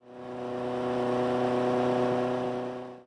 highway / oldcar / tovertake4.ogg